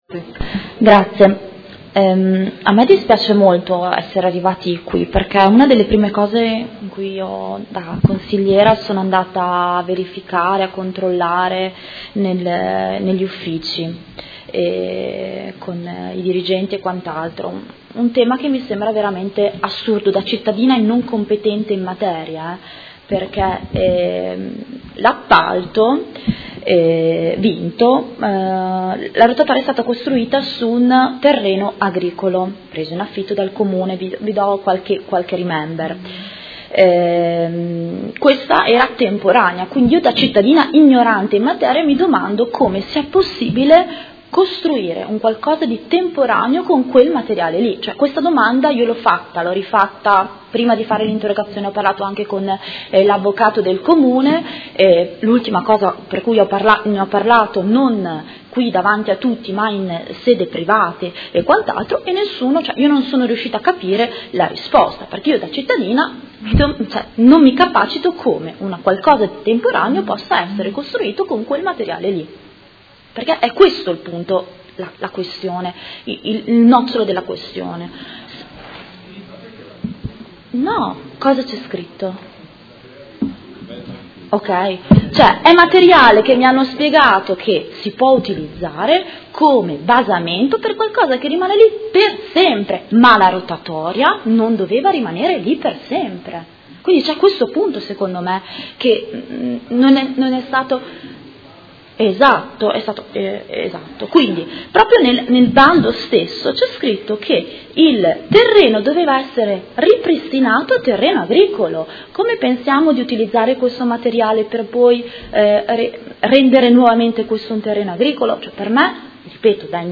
Seduta del 26/03/2018 Dibattito. Ordini del giorno Rotatoria di via Emilia Est